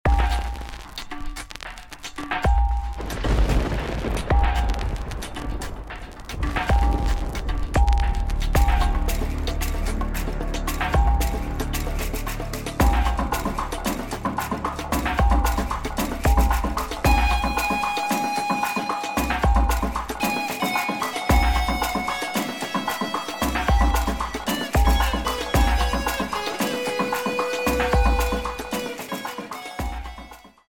10 Music tracks in various dance styles.
Warm-up, Cool Down, Modern, Jazz, Hip Hop